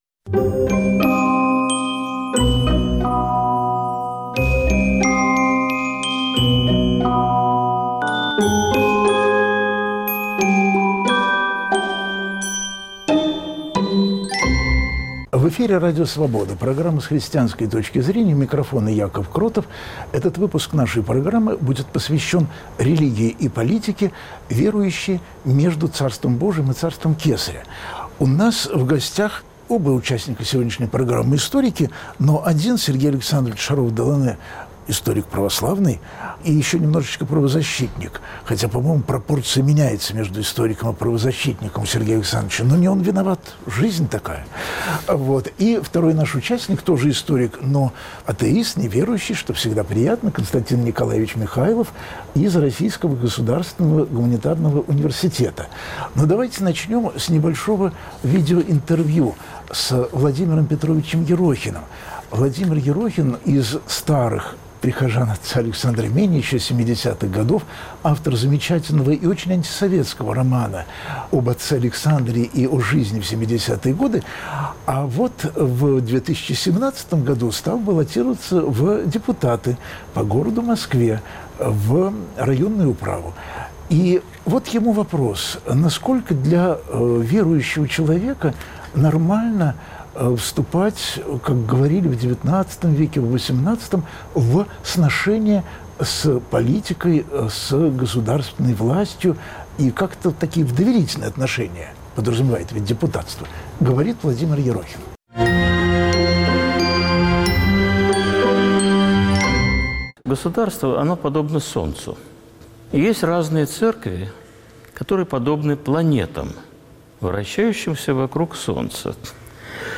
слушают друг друга верующие и неверующие